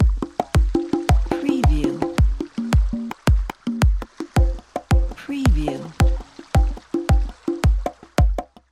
ریتم آماده 6/8 تومبا | دانلود با کیفیت ترین لوپ های 6/8
demo-tumba.mp3